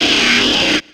Cri de Flobio dans Pokémon X et Y.